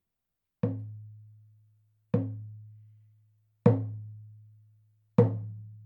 ネイティブ アメリカン（インディアン）ドラム NATIVE AMERICAN (INDIAN) DRUM 14インチ（elk アメリカアカシカ・ワピチ）
ネイティブアメリカン インディアン ドラムの音を聴く
乾いた張り気味の音です